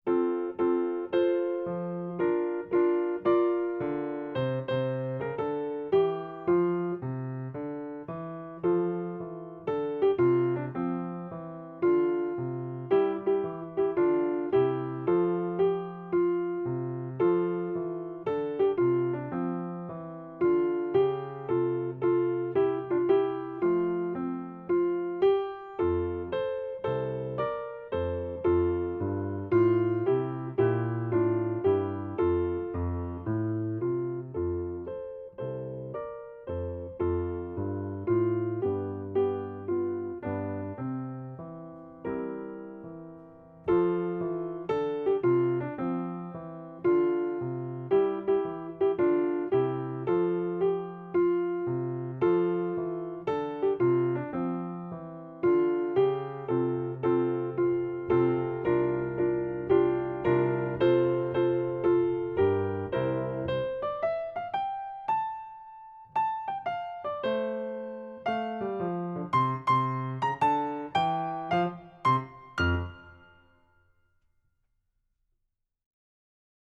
Voicing: Easy Piano